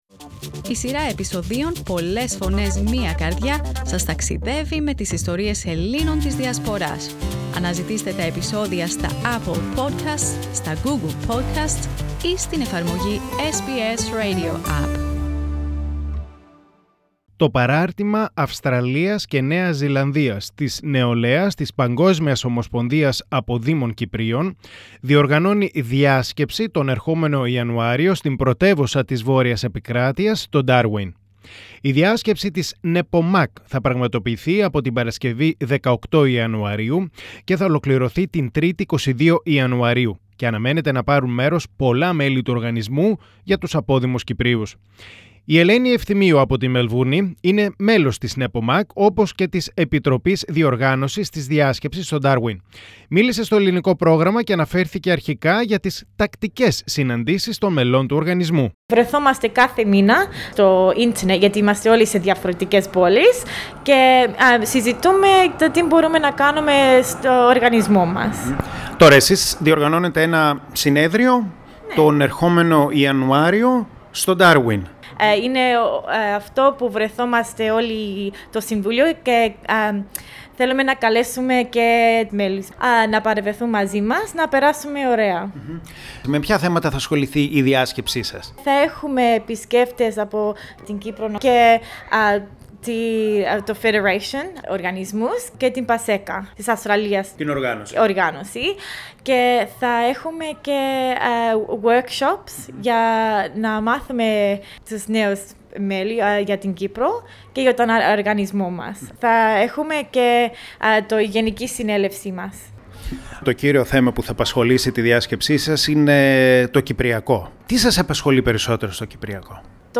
Below the interviews in English: null NEPOMAK Australia & New Zealand prides itself on being one of the most active and energetic members of the global NEPOMAK family, despite the geographical distance from Cyprus.